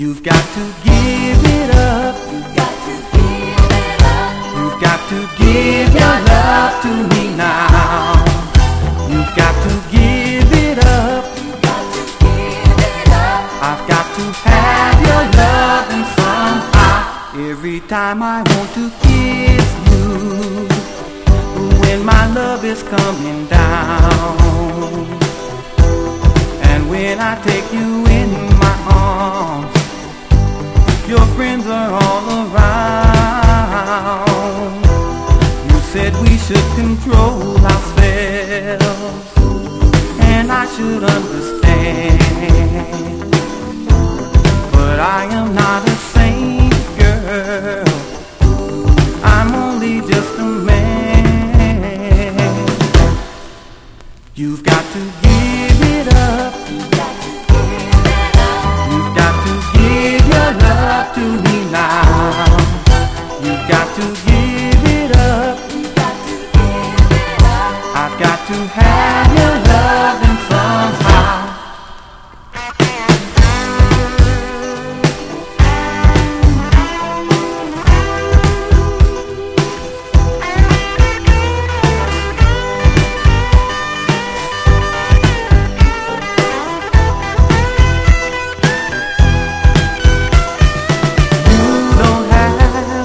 SOUL / SOUL / 70'S～ / DISCO / DANCE CLASSIC / FREE SOUL
A.O.R.～ブルーアイド・ソウル好きにオススメのラヴリーなインスト・ソウルです！